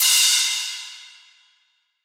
DDW4 CRASH 3.wav